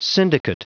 Prononciation du mot syndicate en anglais (fichier audio)
Prononciation du mot : syndicate